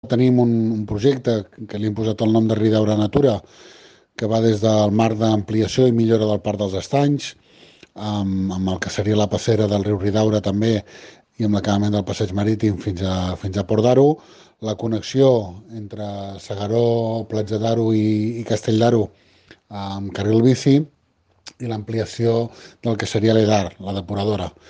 Maurici Jiménez (PSC), alcalde de Castell-Platja d’Aro, ha avançat a Ràdio Capital els projectes que el consistori ja ha presentat als fons europeus anomenats ‘Next Generation’.
TALL-DE-VEU-MAURICI-JIMENEZ-2.mp3